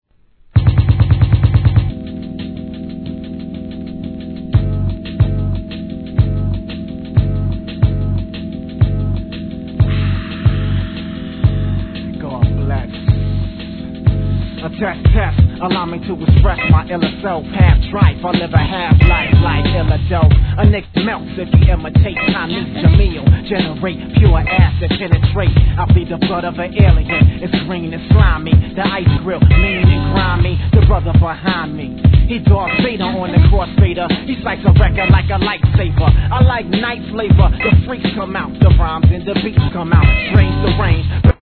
HIP HOP/R&B
シンプルながら煙たくもFATなプロダクション、たまりません!!